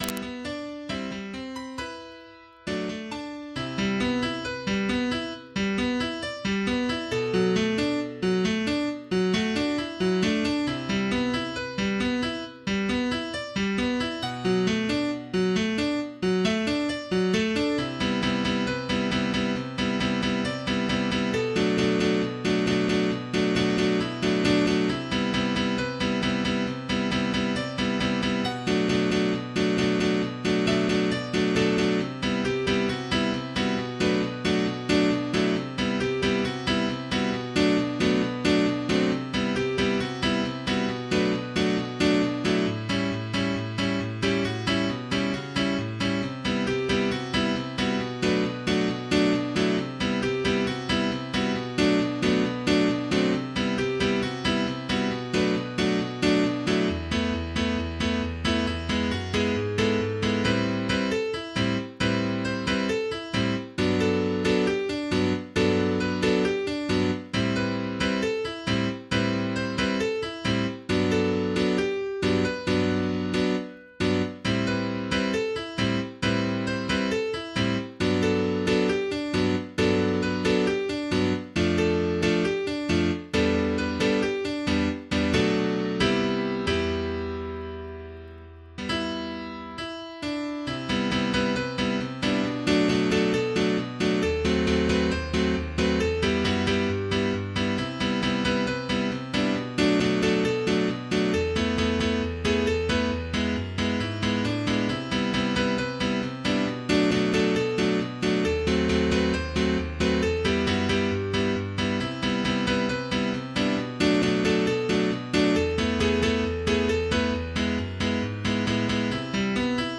MIDI 39.99 KB MP3 (Converted)